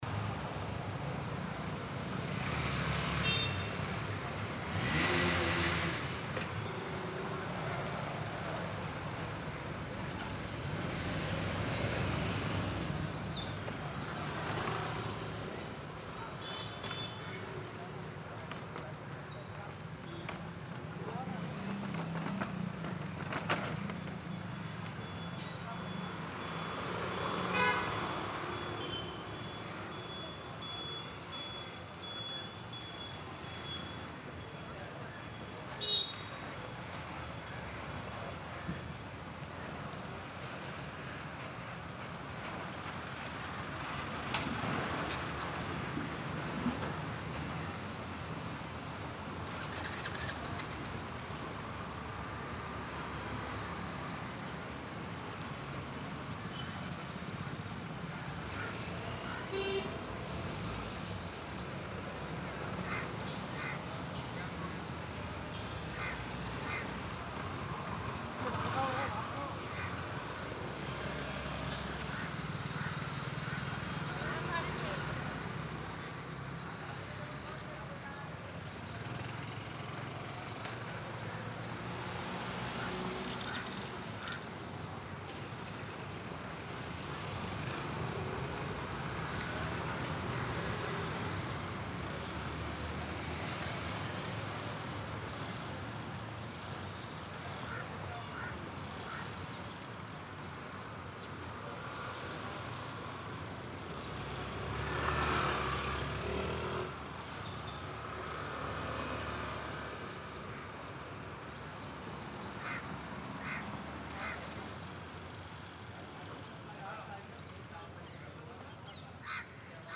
City Road-AMB-026
Constant low vehicle noise for ambience layering
This ambience captures the busy soundscape of a city roadway filled with continuous vehicle movement. The recording includes smooth layers of cars passing, bikes accelerating, distant honks, auto-rickshaws, buses slowing down, tyre noise on asphalt, and subtle engine hums.
The tone remains realistic and dynamic—vehicles come closer, pass by, and fade out naturally, creating a believable urban traffic environment. Occasional crosswalk beeps, distant horns, and low city rumble add depth without overpowering. This ambience reflects a typical Indian city road during the day: active, rhythmic, and full of motion.
City Traffic Ambience
Medium–High
Twentysix-City-Roads-Vehicles.mp3